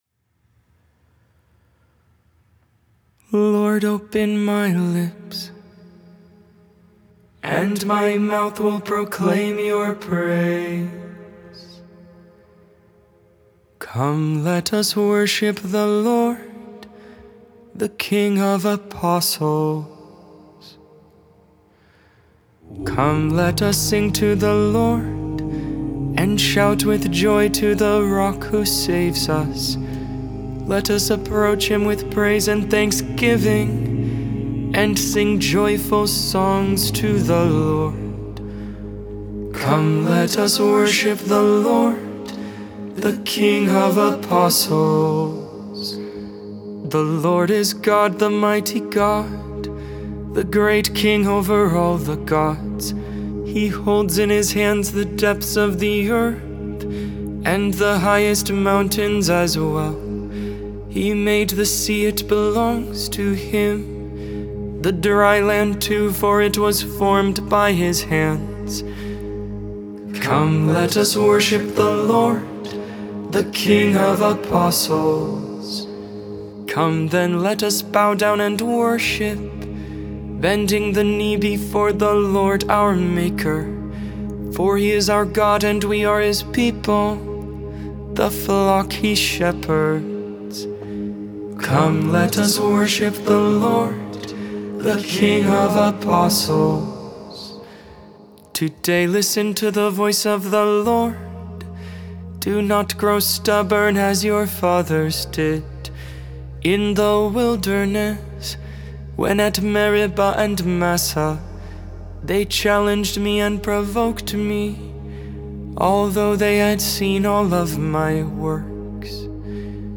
Gregorian tone 6
Gregorian tone 1, minor setting, mixed endings